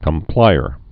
(kəm-plīər)